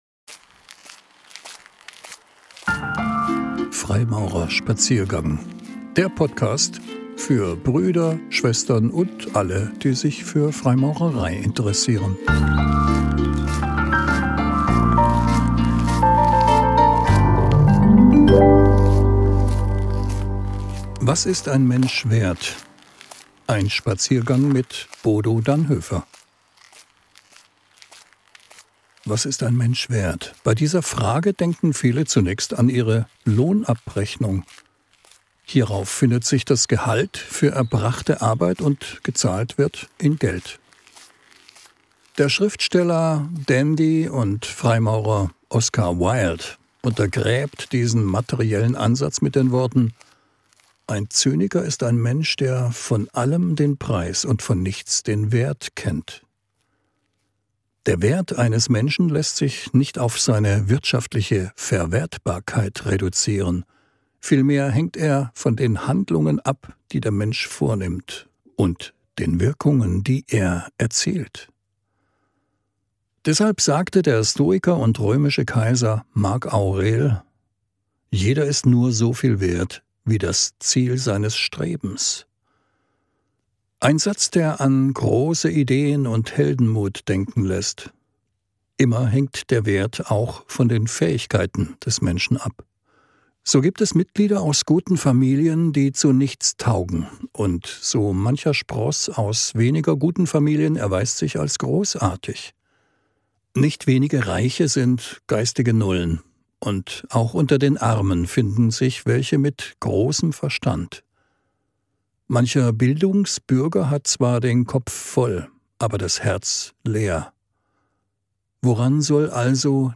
Ausgewählte "Zeichnungen" (Impulsvorträge) von Freimaurern.